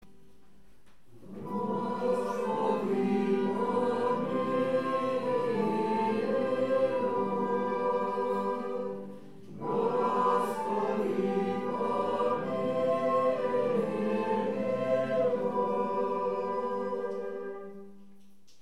Pregària de Taizé
Capella de les Concepcionistes de Sant Josep - Diumenge 30 de novembre de 2014